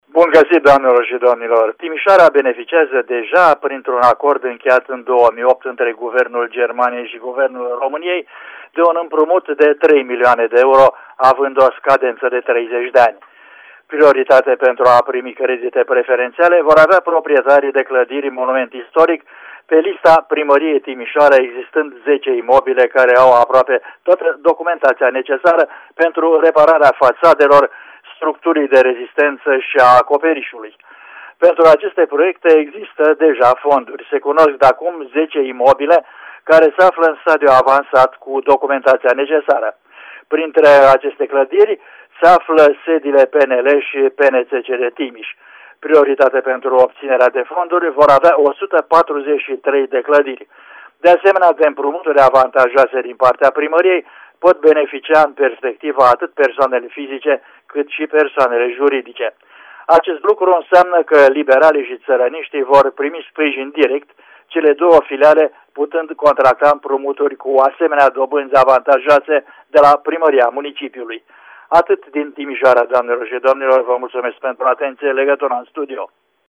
Cu mai multe informaţii corespondentul nostru